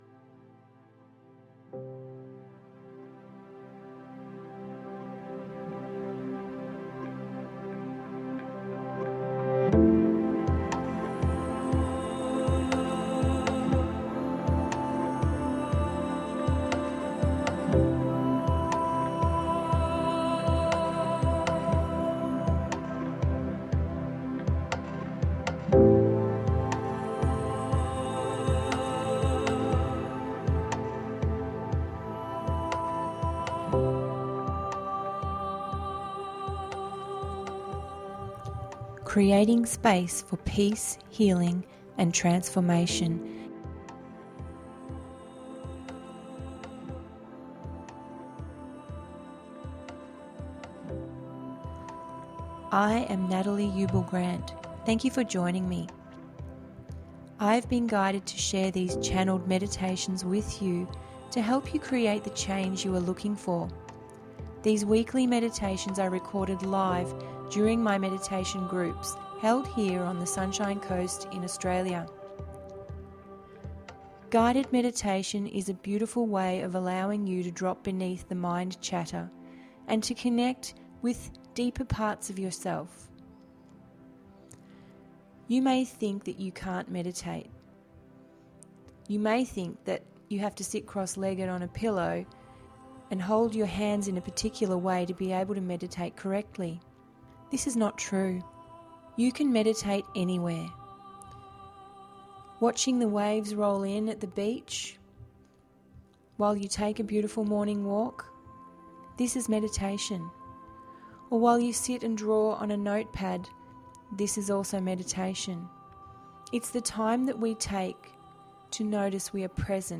Meditation duration approx. 20 mins